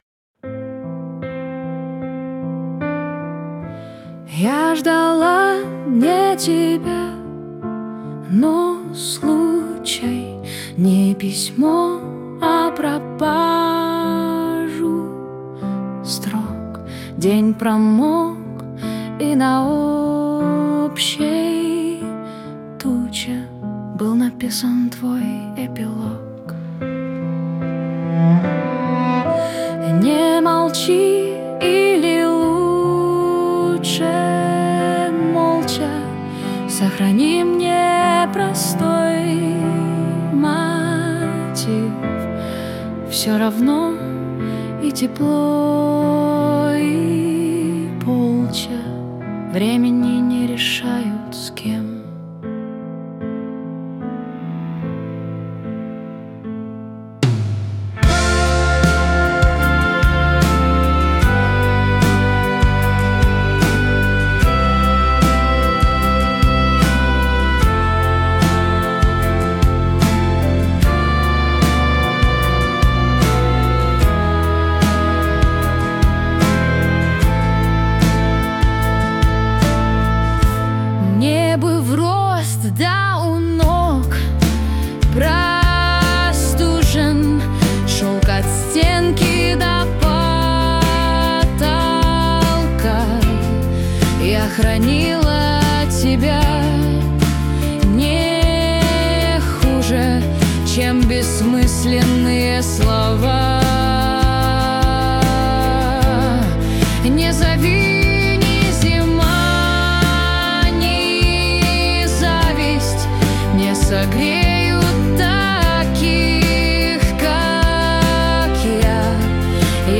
[Интро - инструментально, звучит тиканье часов]